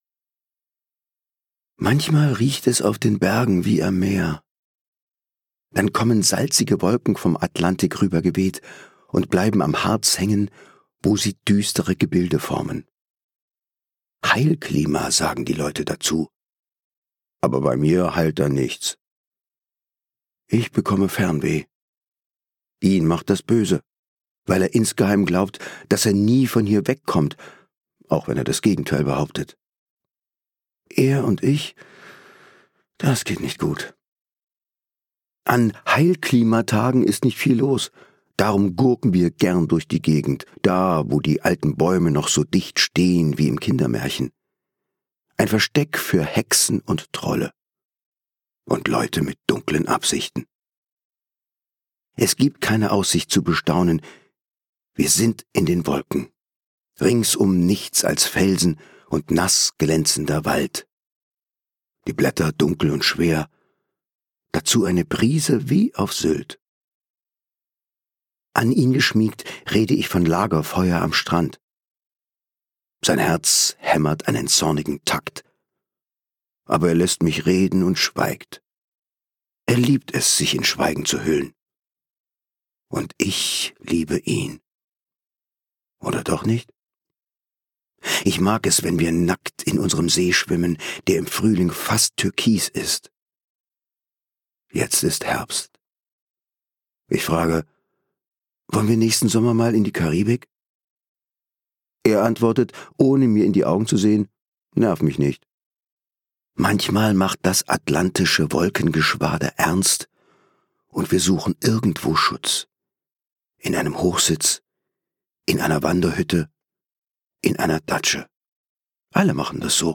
Böse Aussicht - Alexandra Kui, Peter Godazgar | argon hörbuch
Gekürzt Autorisierte, d.h. von Autor:innen und / oder Verlagen freigegebene, bearbeitete Fassung.